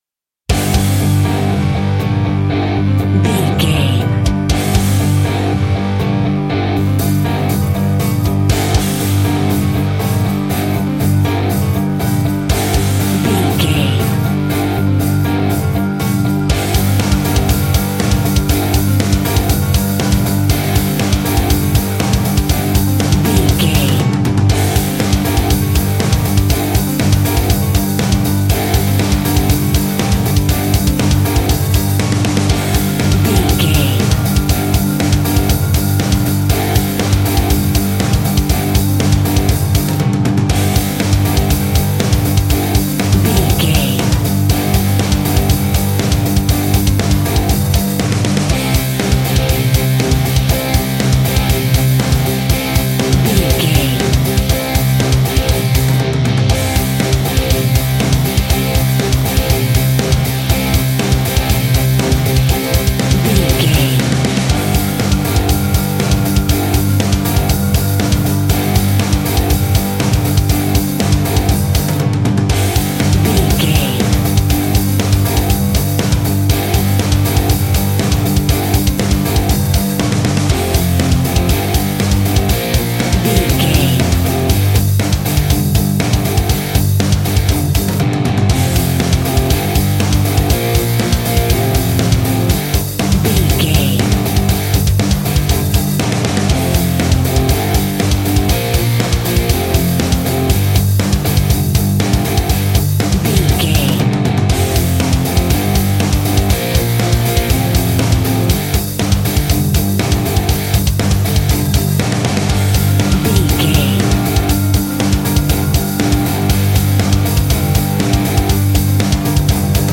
Epic / Action
Fast paced
Aeolian/Minor
hard rock
scary rock
instrumentals
Heavy Metal Guitars
Metal Drums
Heavy Bass Guitars